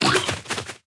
Media:RA_Hog Rider_Base.wavMedia:RA_Hog Rider_Evo.wav UI音效 RA 在角色详情页面点击初级、经典和高手形态选项卡触发的音效